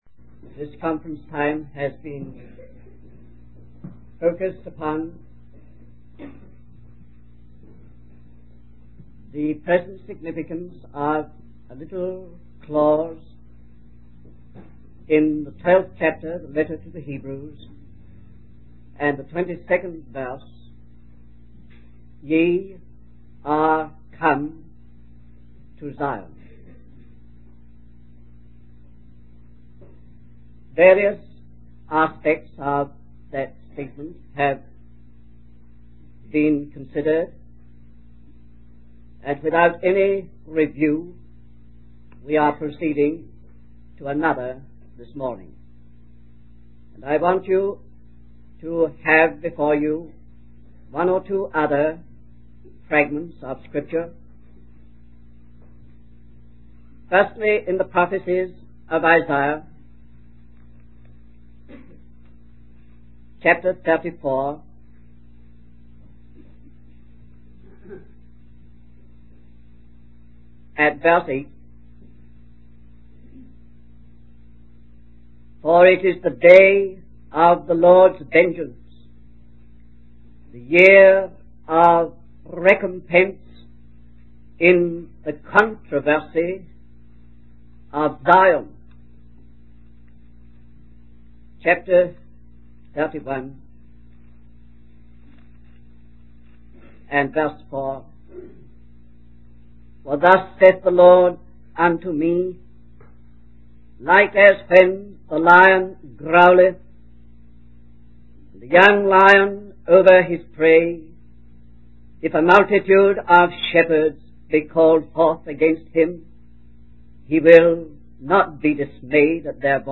In this sermon, the speaker discusses the conflict between God's chosen people and the nations of the world.